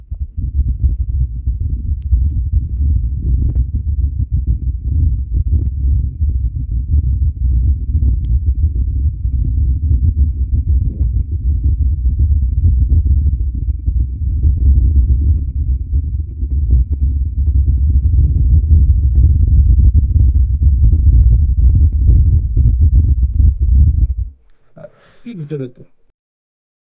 stomach-rumbles--5g5c4kru.wav